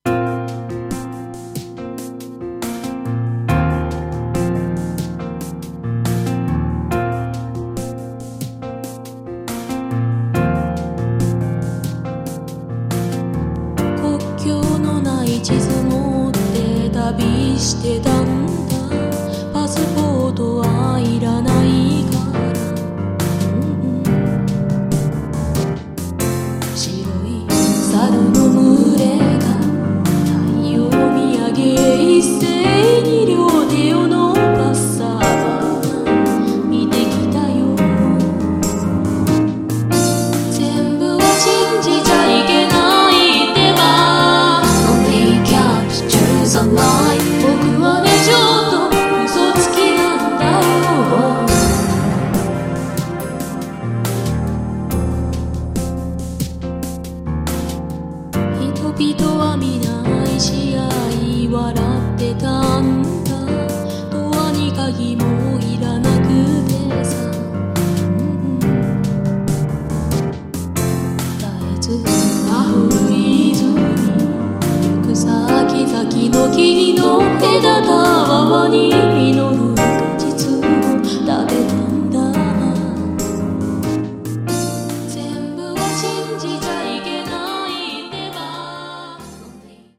歌モノ